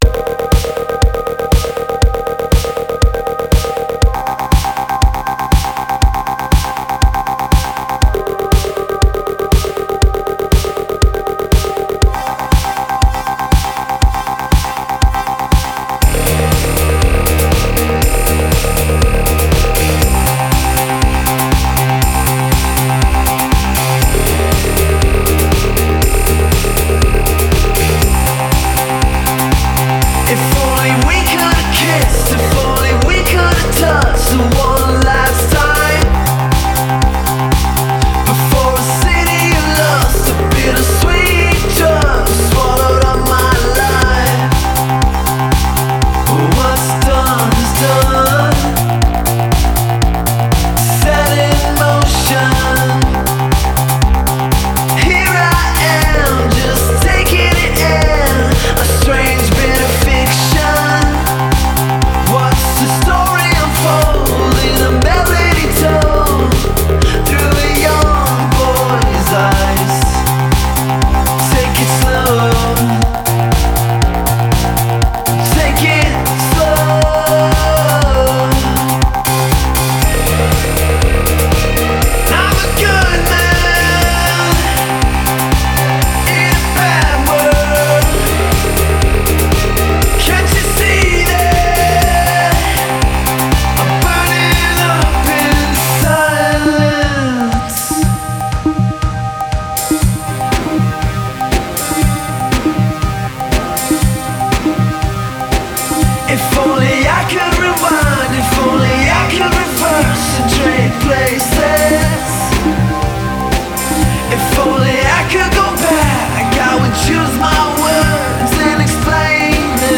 alt-electro songs
sure keeps a fast beat